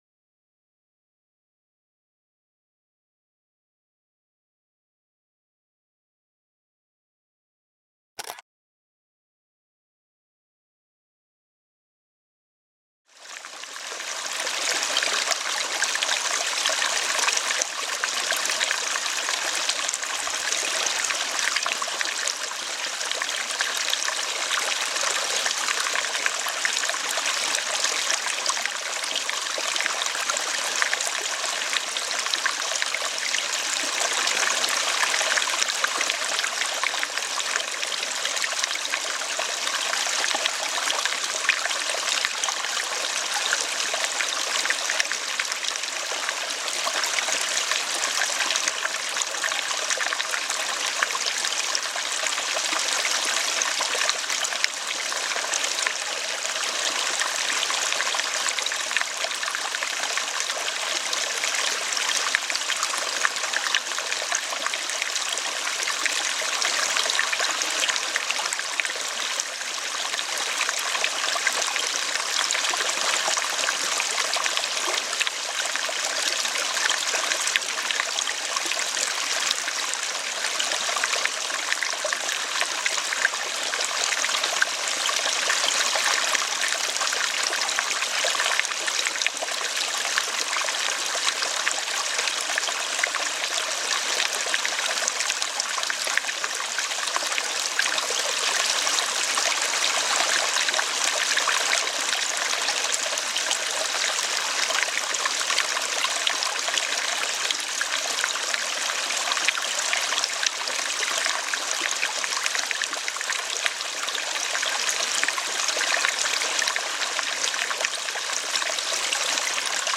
KÖRPER & GEIST-HEILUNG: Waldregen-Heilung mit sanften Tropfen